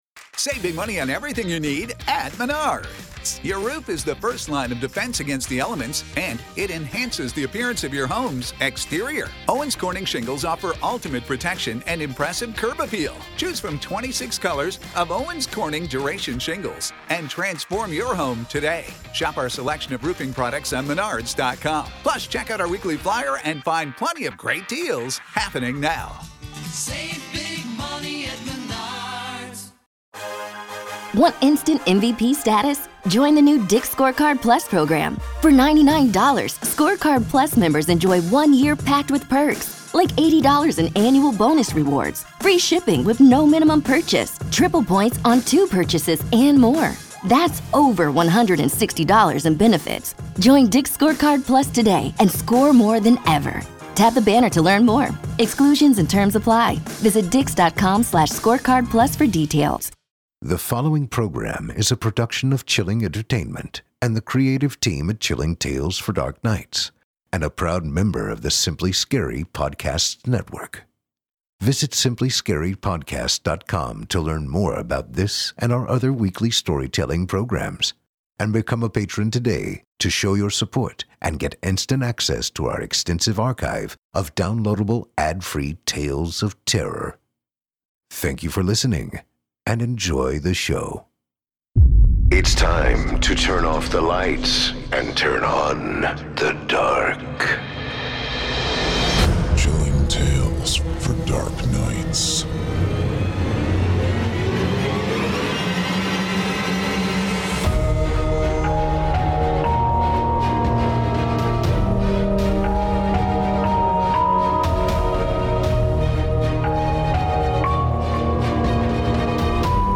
On tonight’s program, we invite you to leave behind your safe reality, and descend with us into the frightening depths of the most terrifying imaginations, with audio adaptations of two rounds of frightening fiction, from authors Chris Fox and J.A. Konrath, about technological trouble and the evils of absolution.